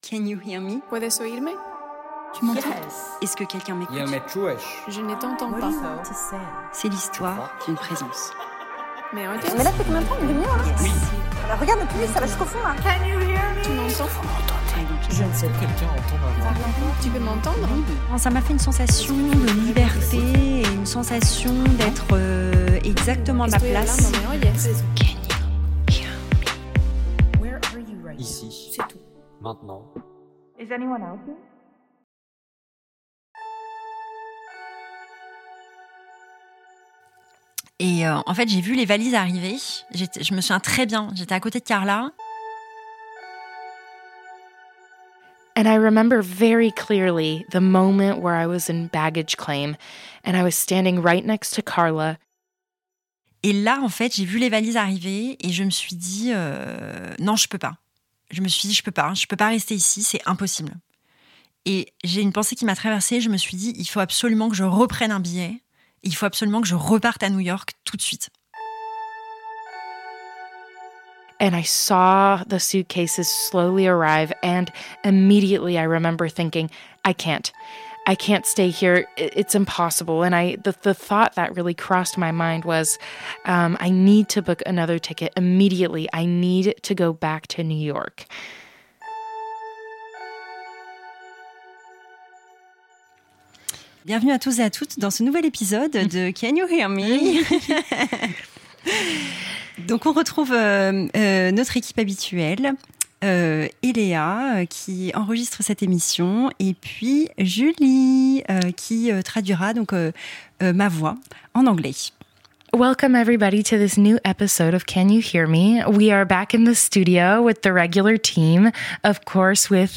Episode 7 • Why I Love New York Part Two (French & English) Partager Type Création sonore Découvertes musicales Culture dimanche 15 mars 2026 Lire Pause Télécharger I come back to New York.